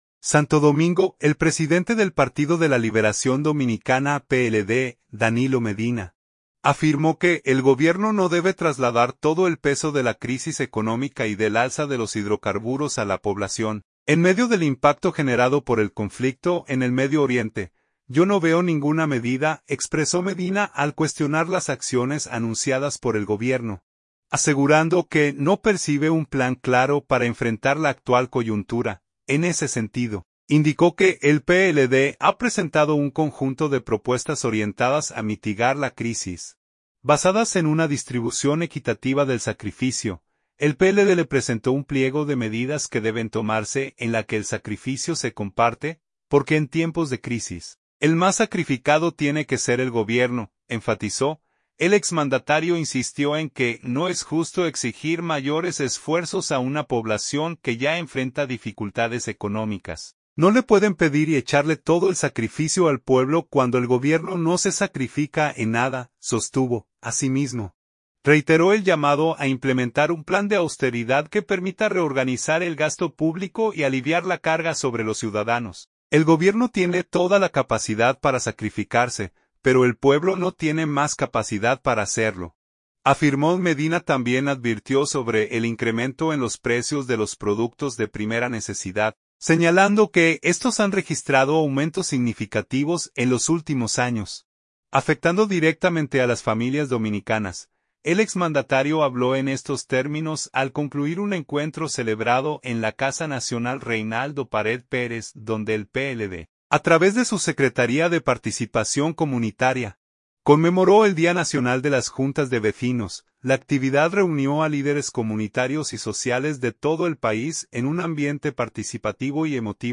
El exmandatario habló en estos términos al concluir un encuentro celebrado en la Casa Nacional Reinaldo Pared Pérez, donde el PLD, a través de su Secretaría de Participación Comunitaria, conmemoró el Día Nacional de las Juntas de Vecinos.